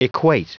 Prononciation du mot equate en anglais (fichier audio)
Prononciation du mot : equate